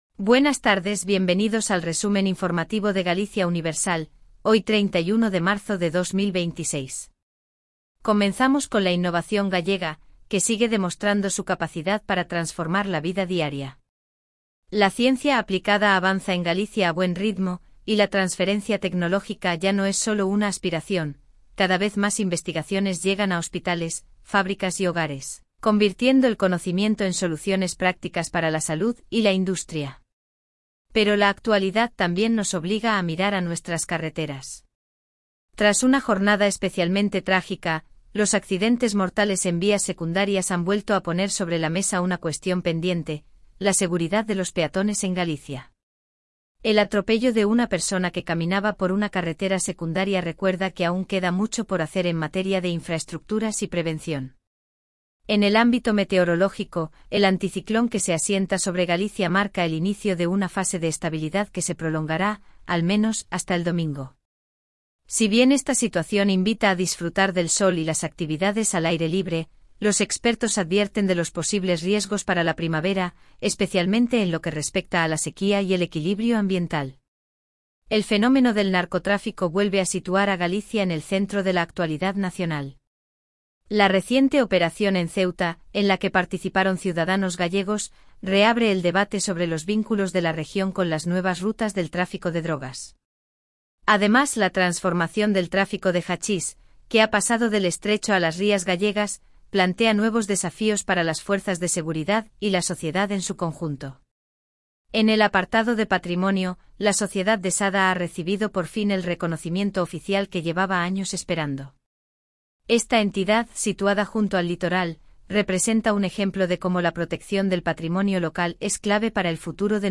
Resumen informativo de Galicia Universal